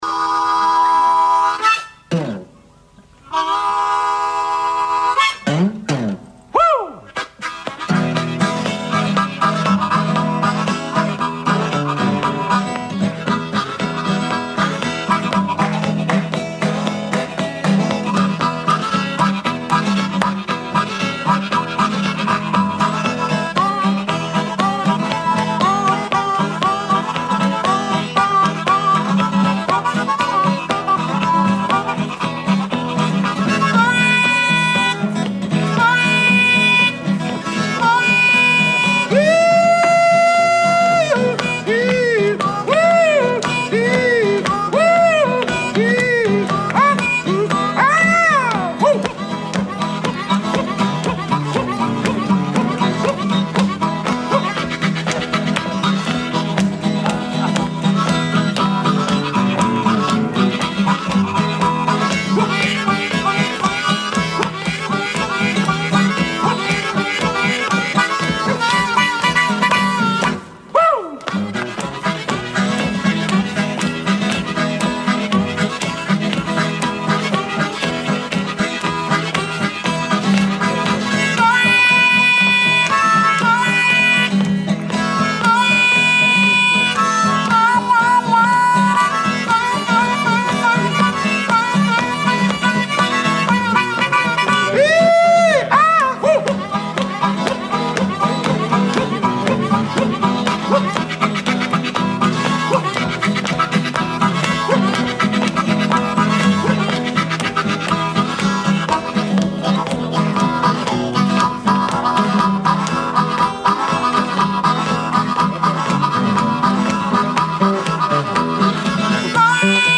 I also got to see and hear the remarkable Blues virtuosos